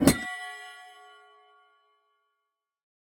Minecraft Version Minecraft Version 25w18a Latest Release | Latest Snapshot 25w18a / assets / minecraft / sounds / block / trial_spawner / open_shutter.ogg Compare With Compare With Latest Release | Latest Snapshot
open_shutter.ogg